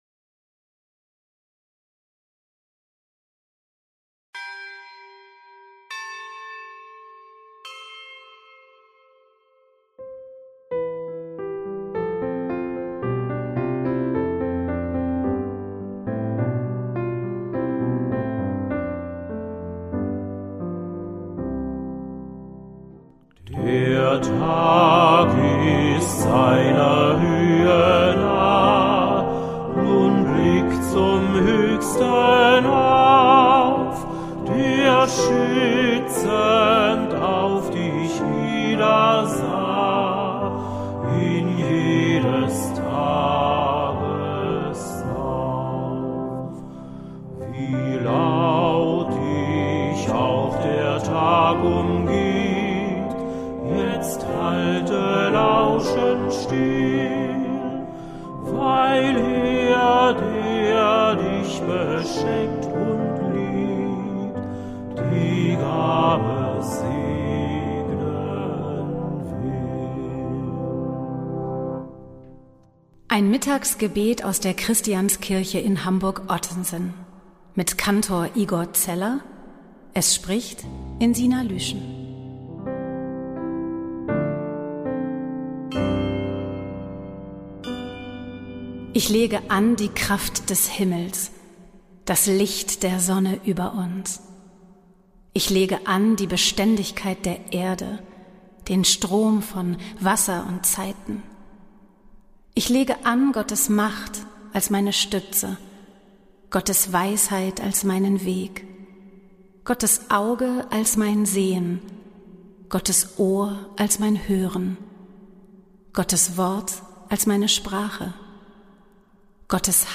Mitagsgebet aus der Christianskirche Hamburg-Ottensen
Gebete, Texte und Gesänge aus der Christianskirche